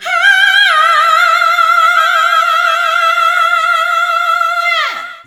SCREAM 2  -R.wav